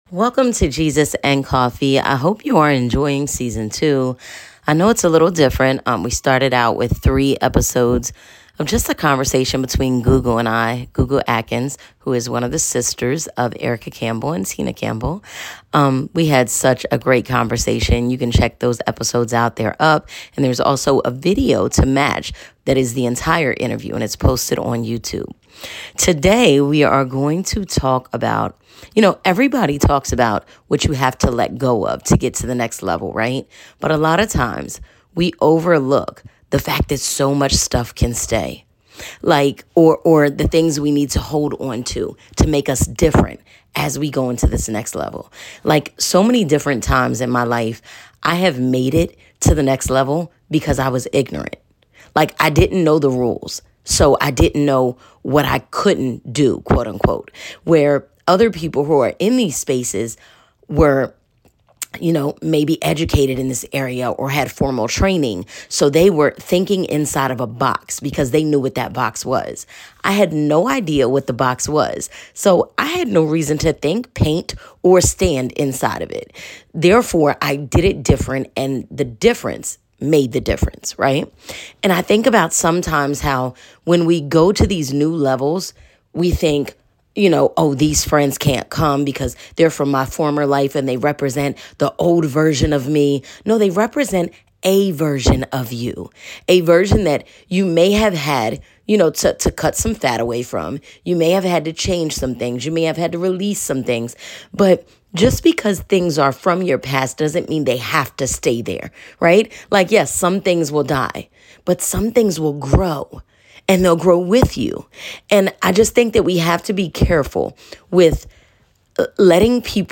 Having a conversation with a friend; designed to help sprinkle some upbeat positivity in the listeners day. There will be storytelling, the occasional guest interview, and biblical reference to help set the tone.